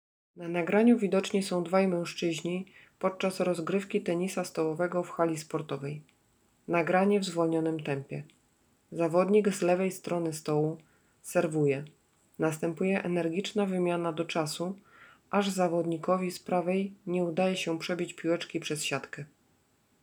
Na koniec prezentujemy jedną z akcji, która rozgrywała się podczas bardzo emocjonującego meczu finałowego.